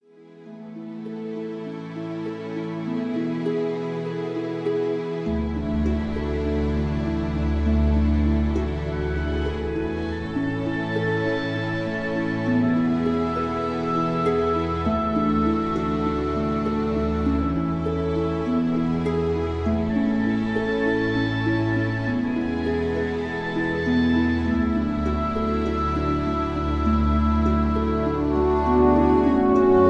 (Key-a, Tono de A) Karaoke MP3 Backing Tracks